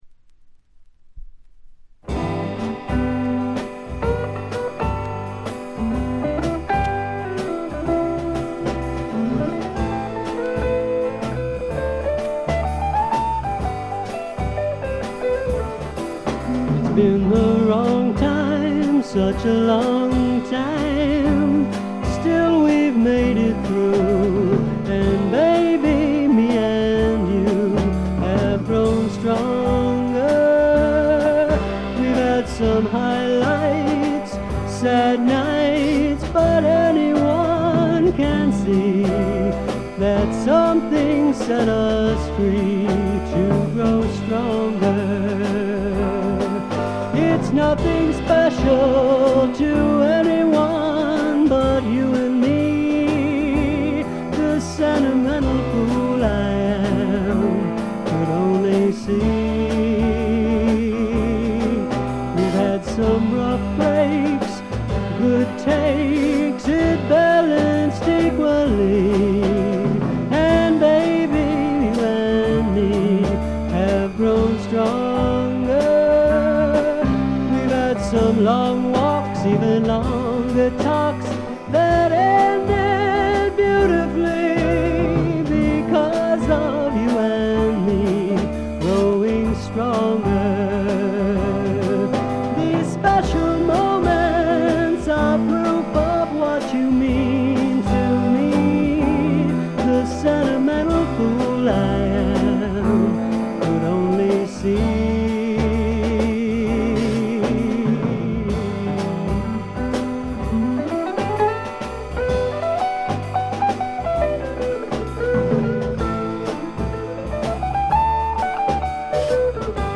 というわけで一度聴いたらクリアトーンのギターの音色が頭から離れなくなります。
特異な世界を見せつけるアシッド・フォークの傑作です。
試聴曲は現品からの取り込み音源です。
Guitar, Vocals, Producer, Written-By, Arranged By ?